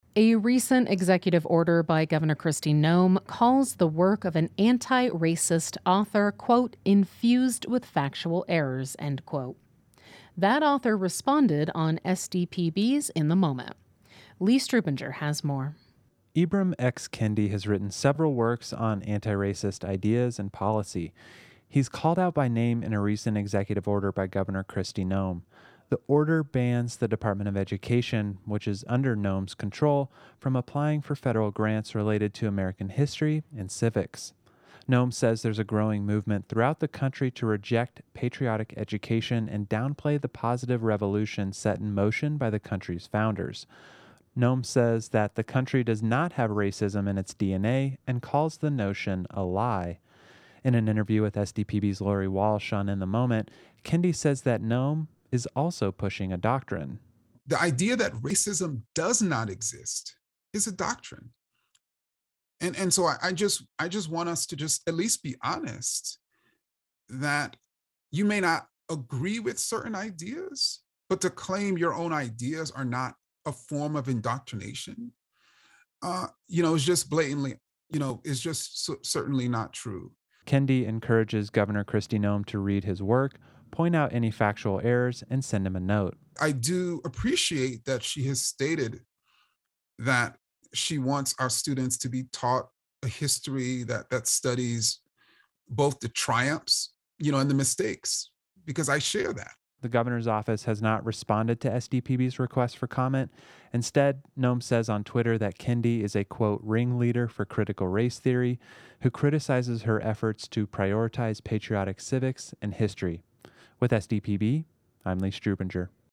This story comes from a recent interview on SDPB's weekday radio program, "In the Moment."